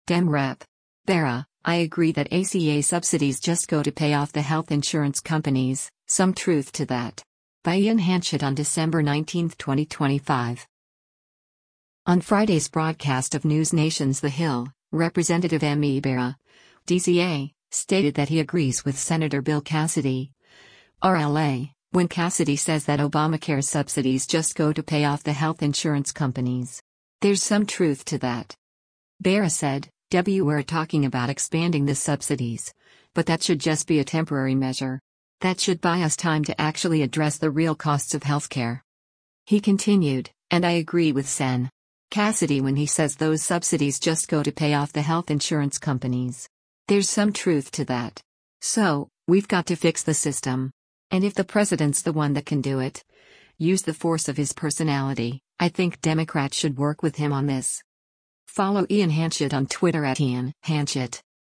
On Friday’s broadcast of NewsNation’s “The Hill,” Rep. Ami Bera (D-CA) stated that he agrees with Sen. Bill Cassidy (R-LA) when Cassidy says that Obamacare subsidies “just go to pay off the health insurance companies.